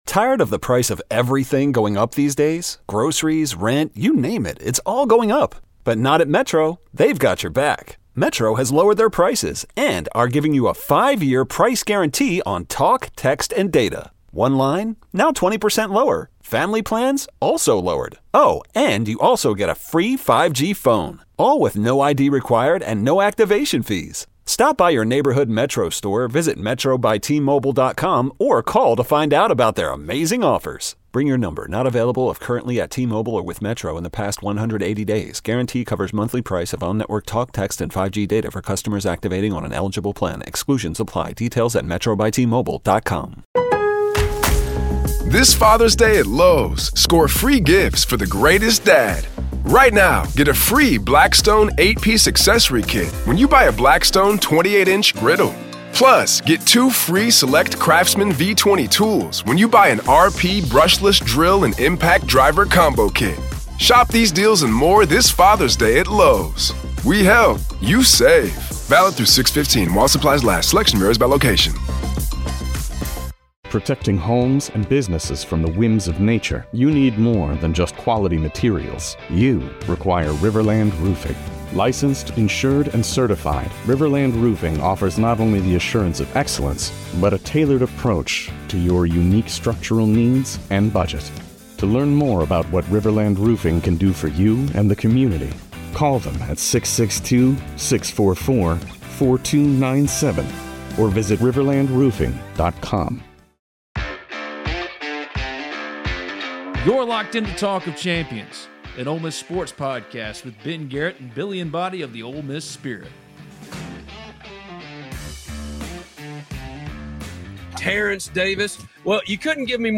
Terence Davis returns to Ole Miss for Celebrity Softball Game